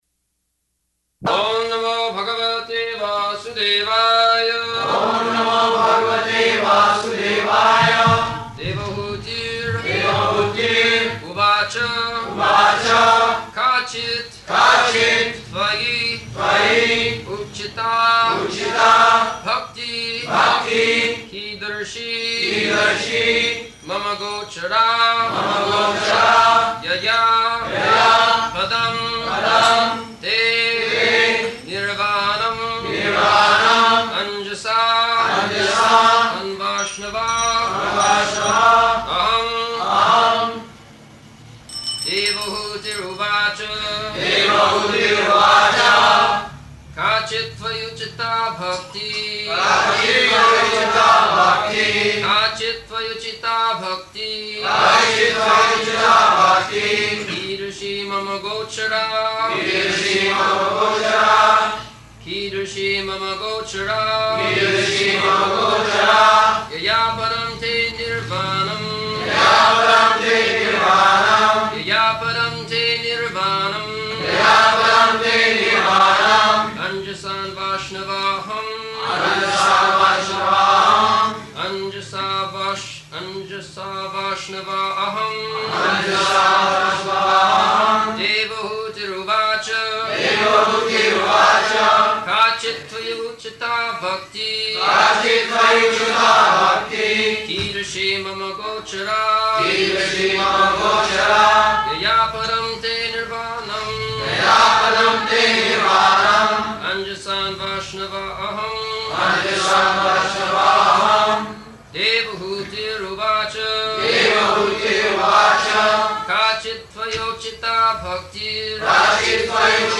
-- Type: Srimad-Bhagavatam Dated: November 28th 1974 Location: Bombay Audio file
[devotees repeat]